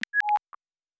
pgs/Assets/Audio/Sci-Fi Sounds/Interface/Data 03.wav at 7452e70b8c5ad2f7daae623e1a952eb18c9caab4